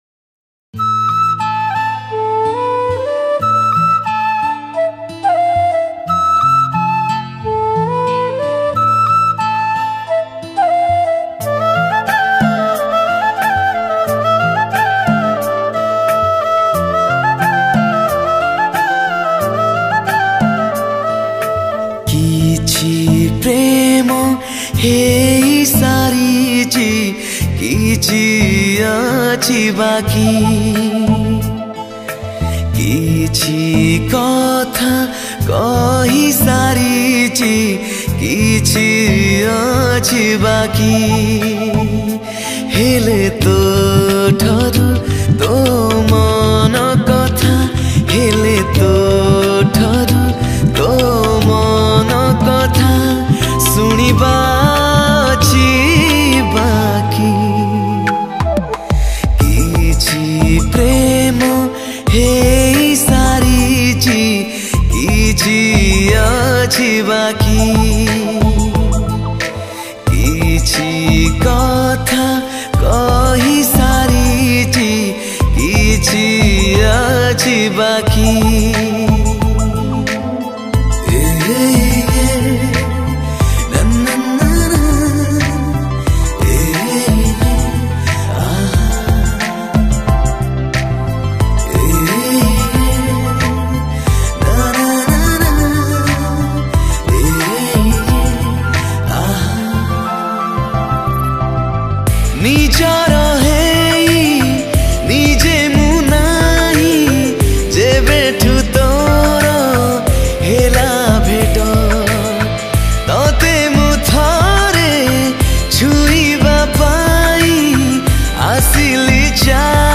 Odia New Sad Song